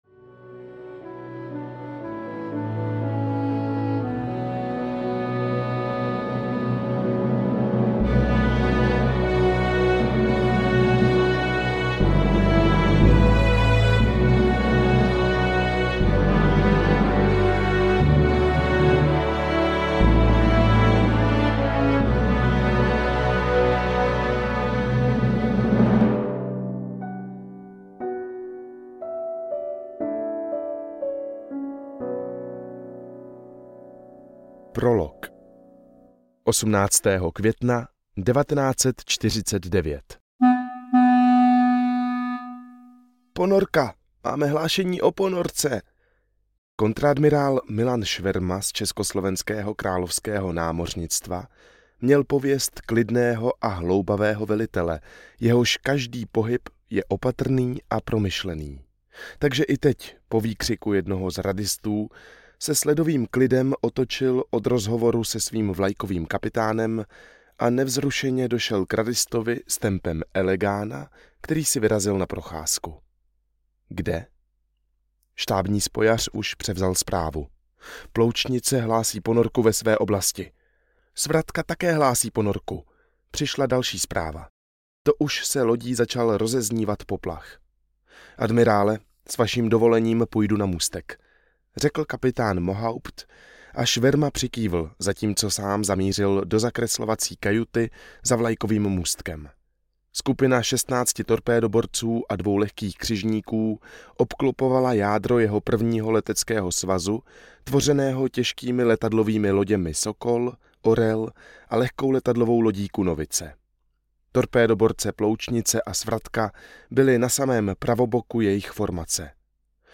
Bitva o české země audiokniha
Ukázka z knihy